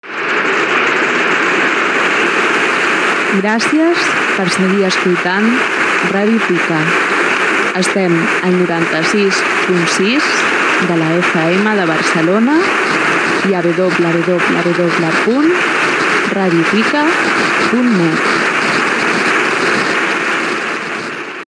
Indicatiu de la ràdio i adreça web
Banda FM